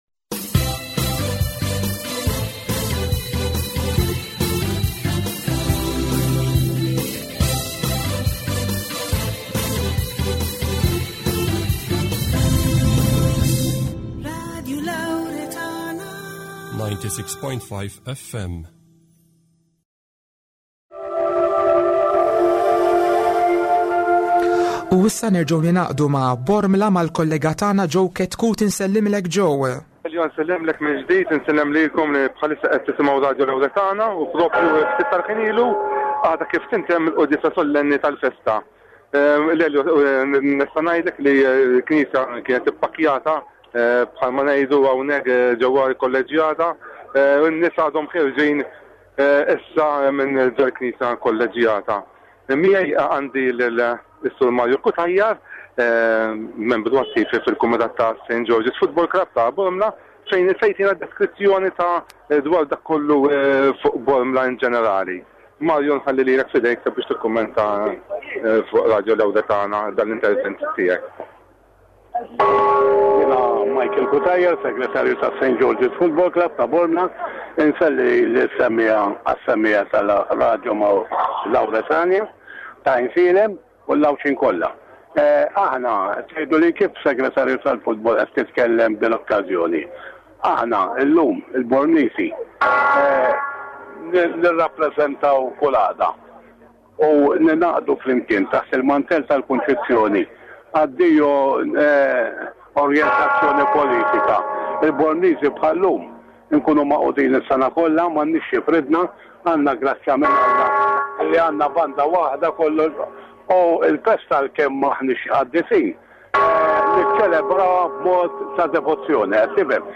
Konċelebrazzjoni Solenni tal-Festa tal-Immakulata Kunċizzjoni Xandira minn Bormla